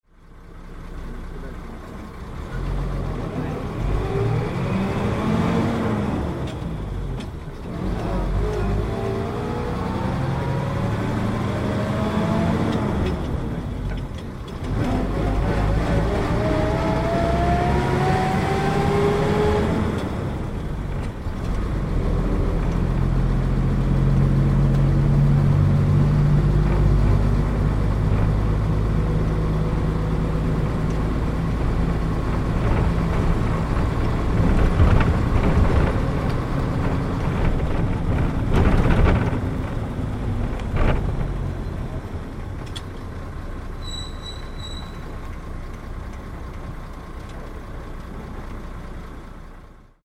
走行音(50秒・978KB)